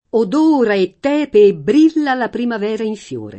od1ra e tt$pe e bbr&lla la primav$ra in fL1re] (Carducci); con tepenti Linfe [